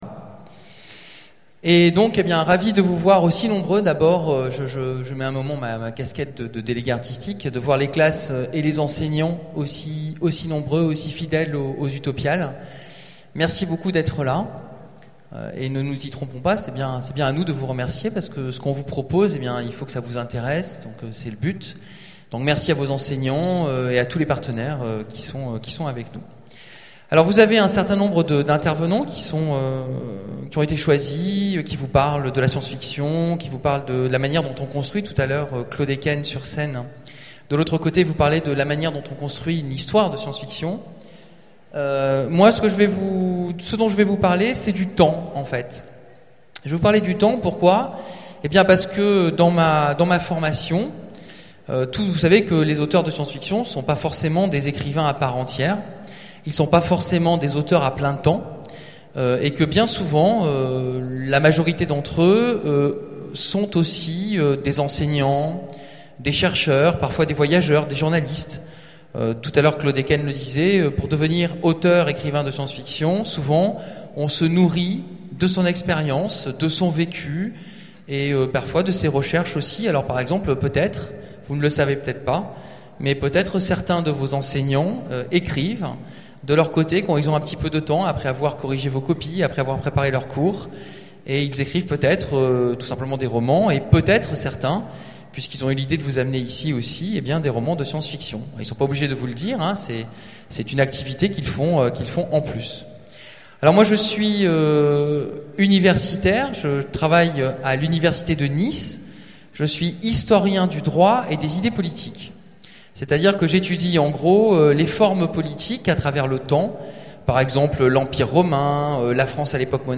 Utopiales 12 : Conférence